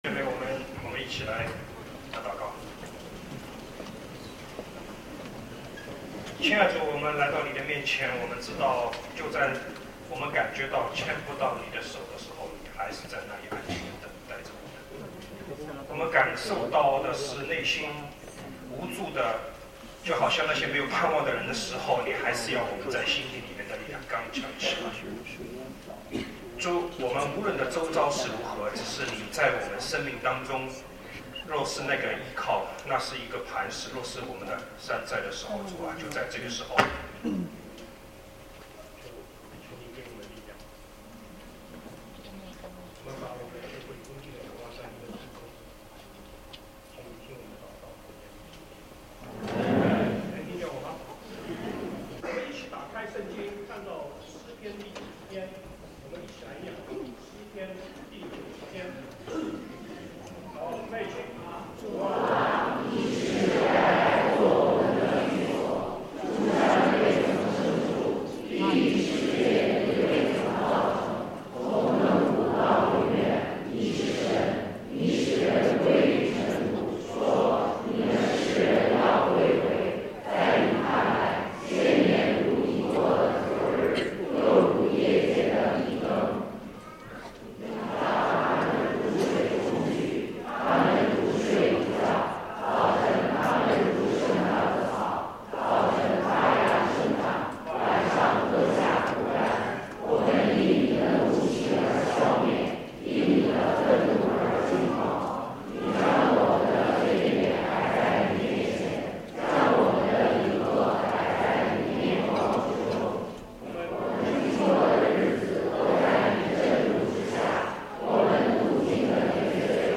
IMS Sermons